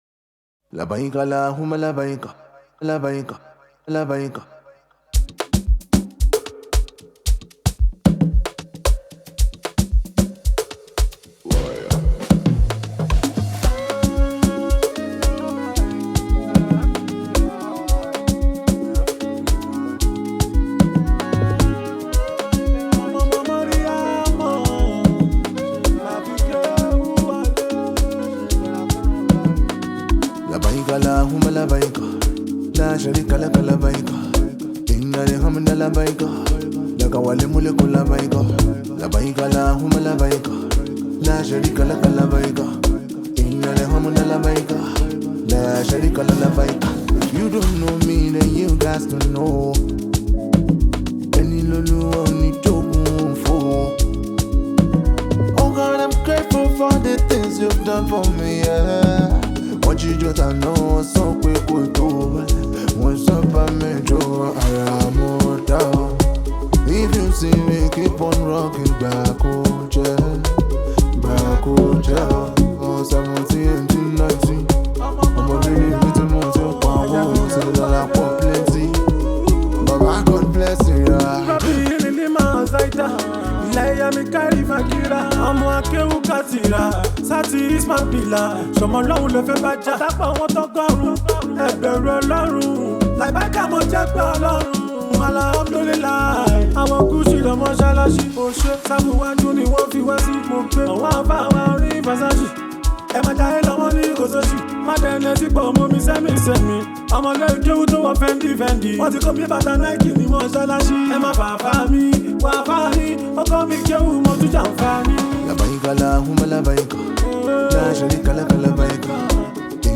Yoruba Islamic Music 0
Nigerian Yoruba Fuji track